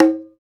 PRC XCONGA15.wav